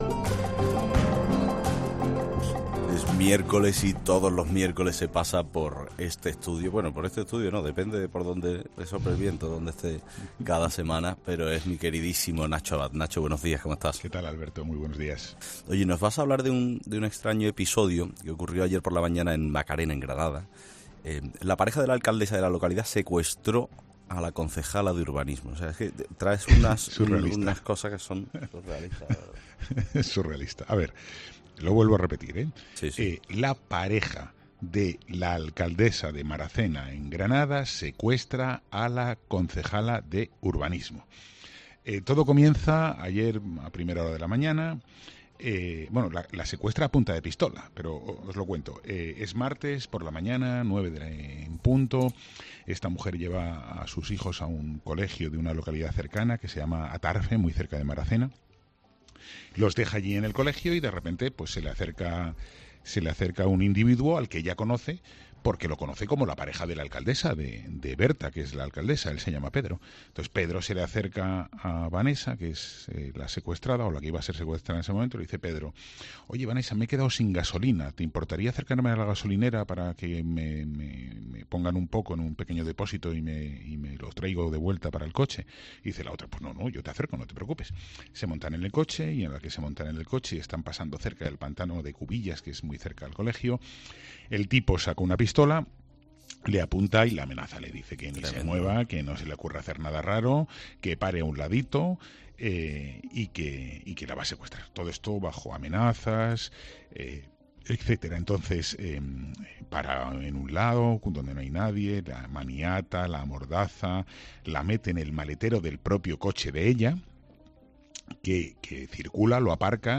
Nacho Abad explica en 'Herrera en COPE' el secuestro de la concejala de Urbanismo de Maracena